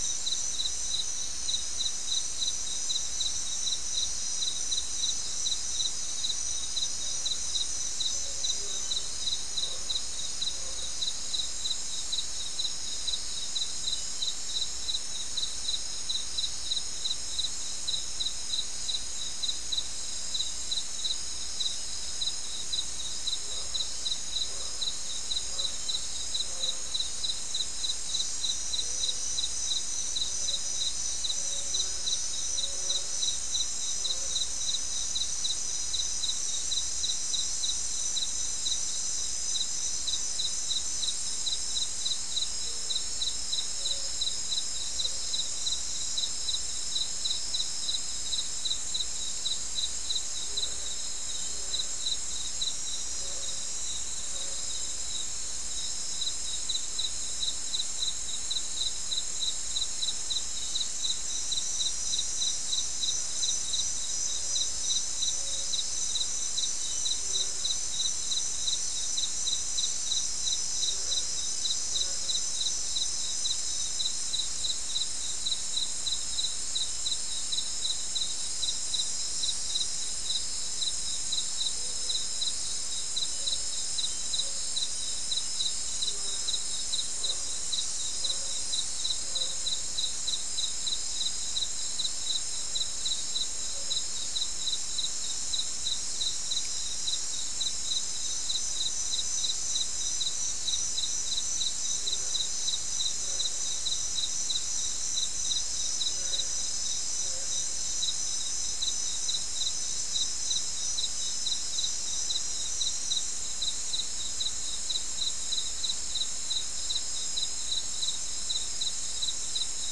Soundscape Recording Location: South America: Guyana: Mill Site: 3
Recorder: SM3